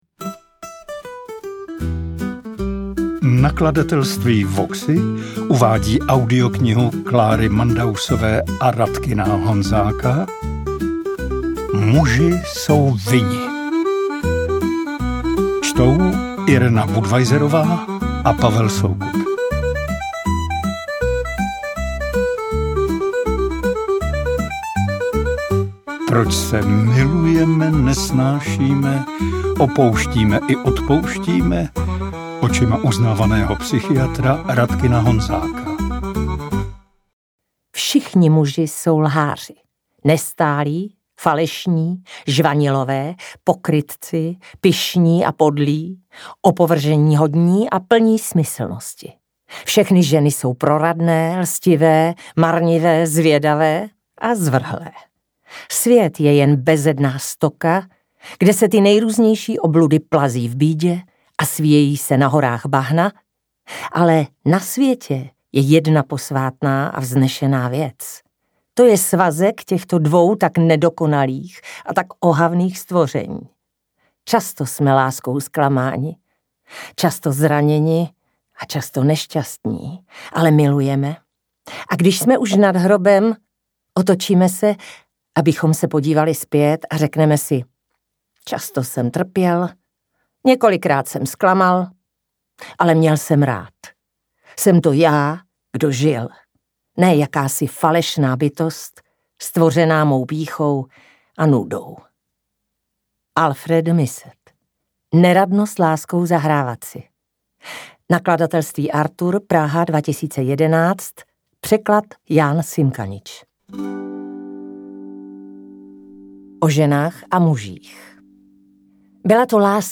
Interpreti:  Irena Budweiserová, Pavel Soukup